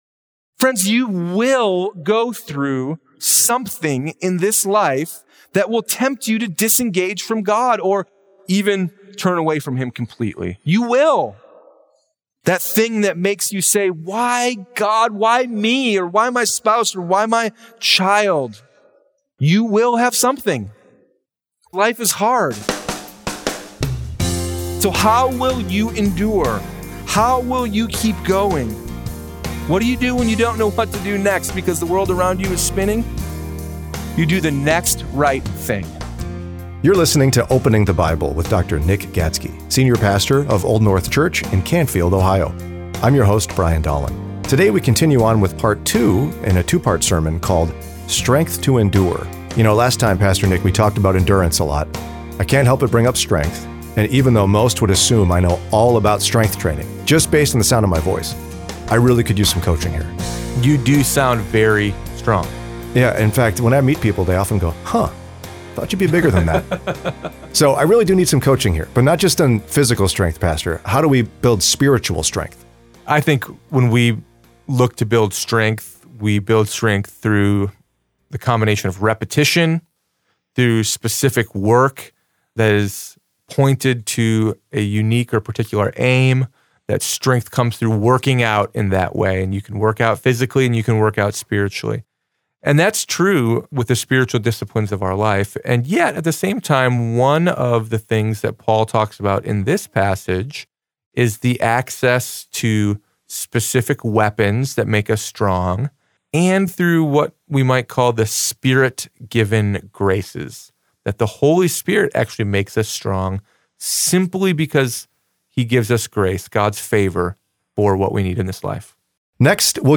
Sermon Library – Old North Church